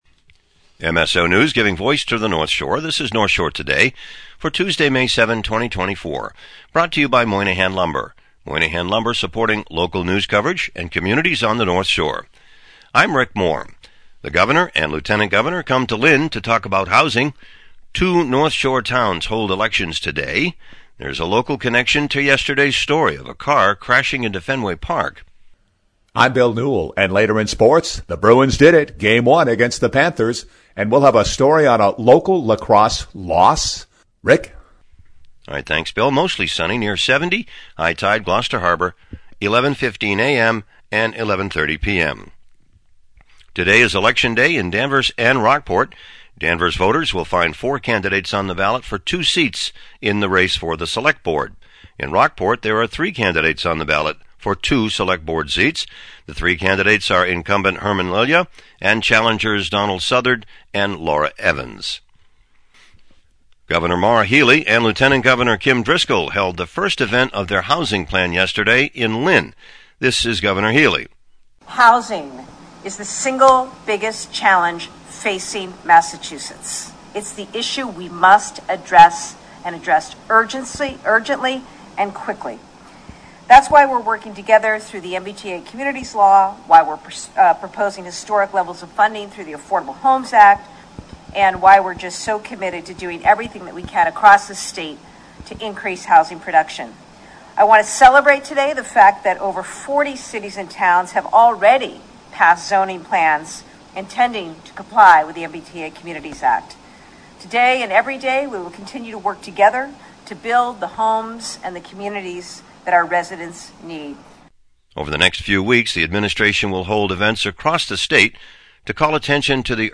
North Shore Today Extra – Audio Clips from Lynn’s GAR Hall Groundbreaking